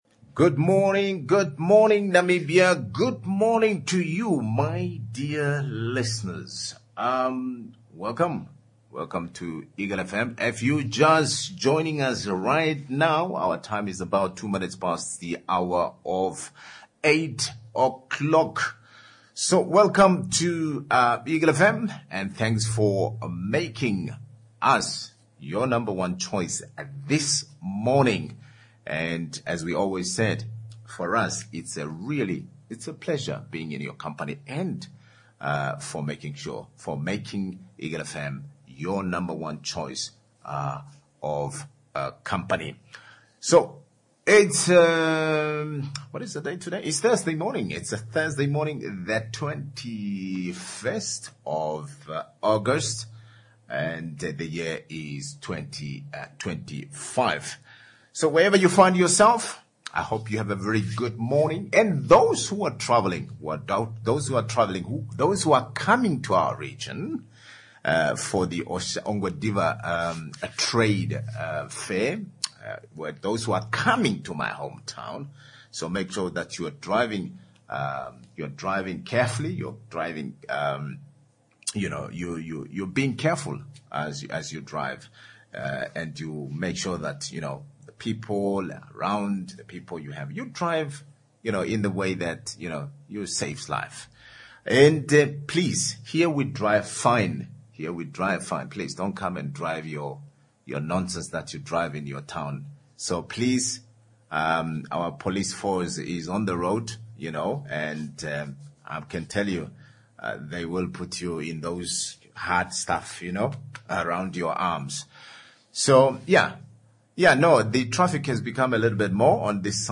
Governor Sinimbo Calls for Investment Drive in Kavango West 2. Can Green Finance Solve Namibia Energy Woes? Interviewed